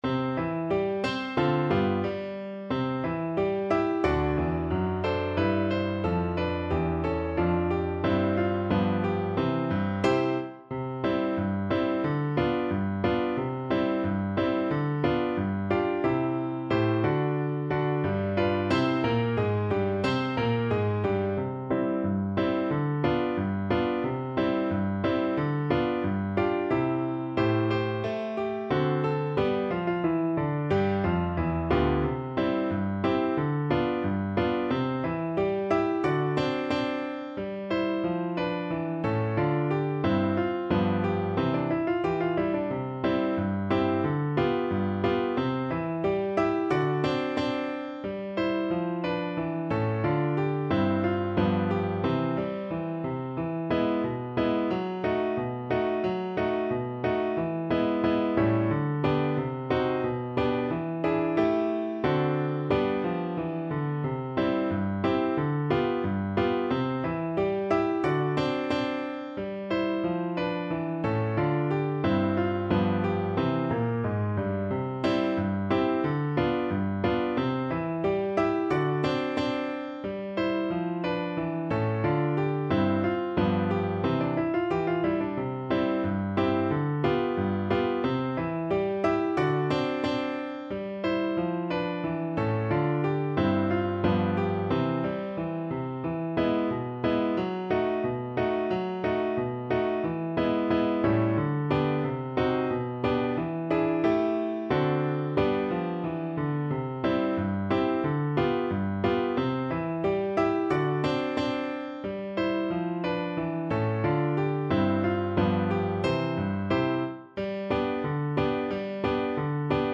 =90 Fast and cheerful
Pop (View more Pop French Horn Music)